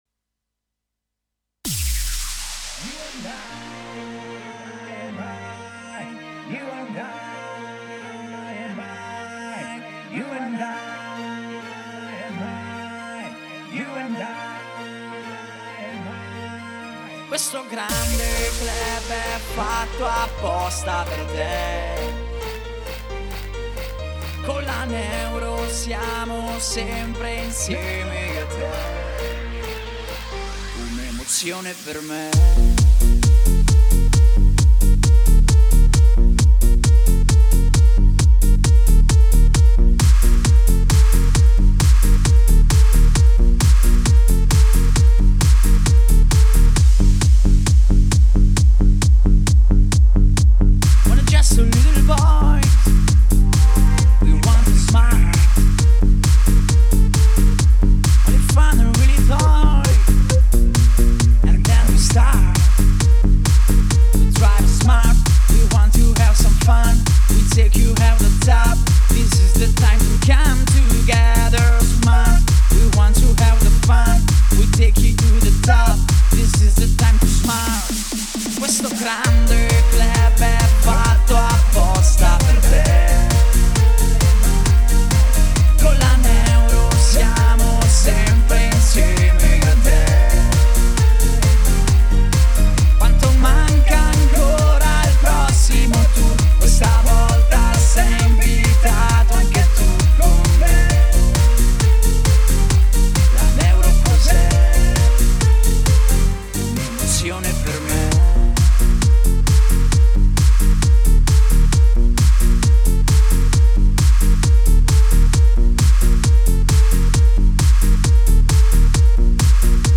SmartClubItalia-Dance.mp3